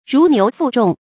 如牛負重 注音： ㄖㄨˊ ㄋㄧㄨˊ ㄈㄨˋ ㄓㄨㄙˋ 讀音讀法： 意思解釋： 像牛負擔著沉重的東西。比喻負擔很重。